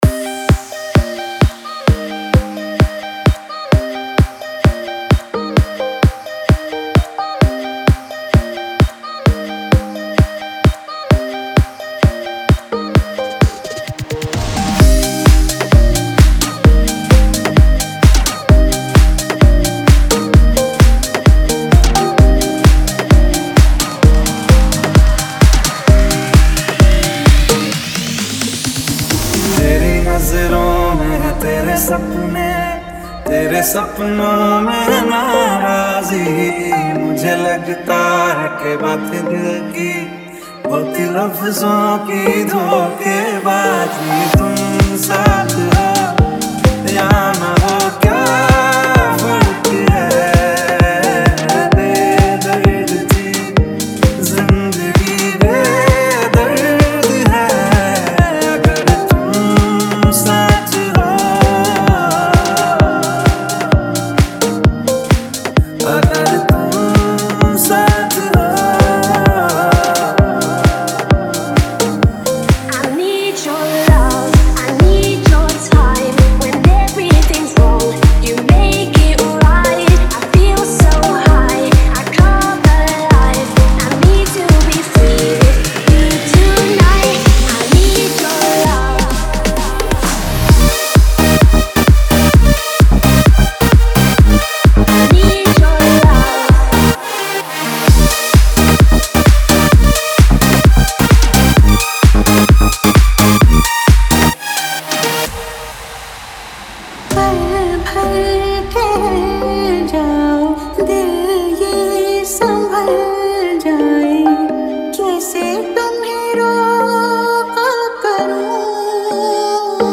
best dj song for dance